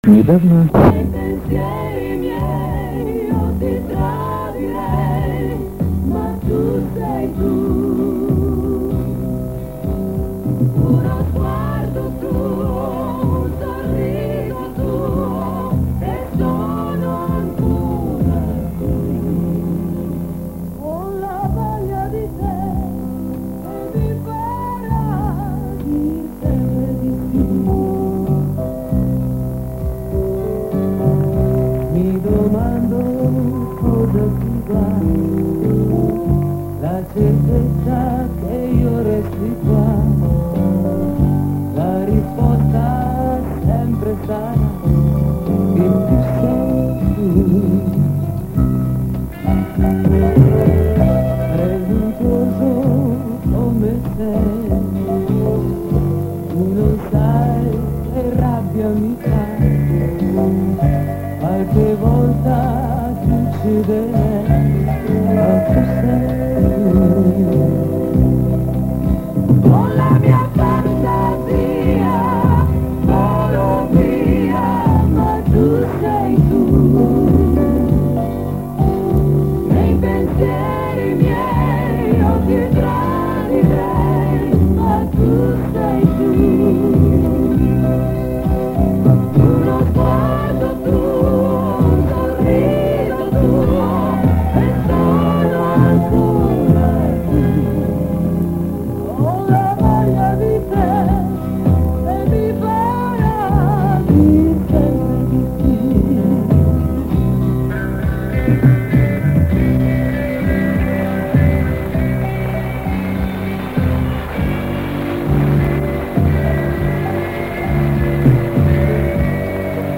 Запись по звукам с концерта, но с какого?
Слышен комментарий Виктора Татарского.